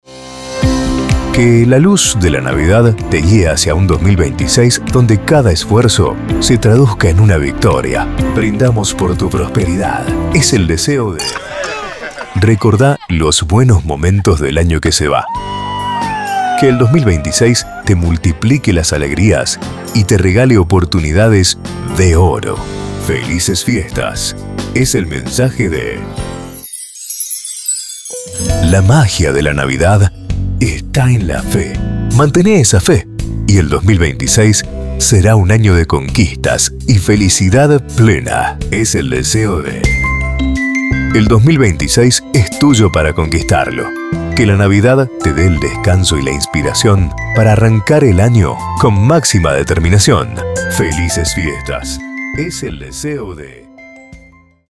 Salutaciones premium para venderle a tus anunciantes o personalizarlas con los datos de tu radio.
✅ Fondos musicales y FX de máxima calidad.